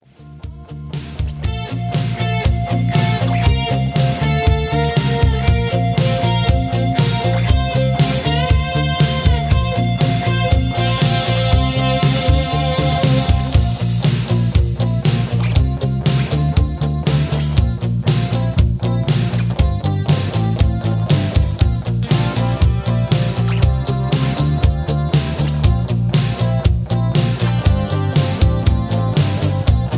karaoke, rock and roll